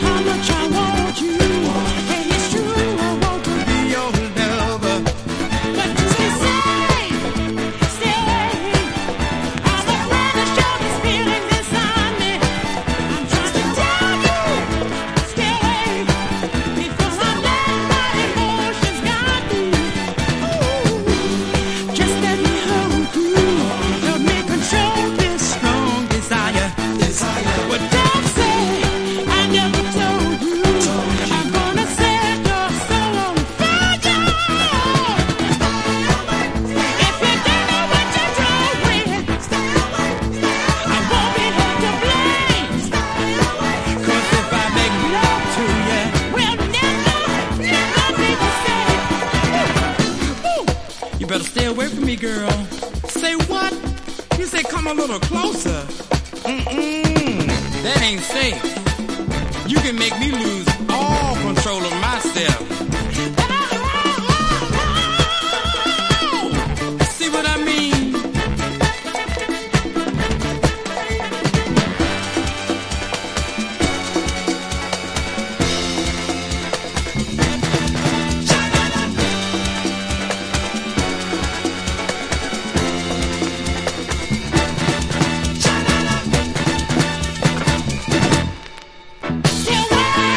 数曲の勢いのあるディスコナンバーと対照的にメロウグルーヴなボーカル曲が映える好バランス盤。
所によりノイズありますが、リスニング用としては問題く、中古盤として標準的なコンディション。